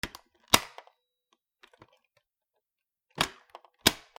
ポット ふたを閉める
『チャ』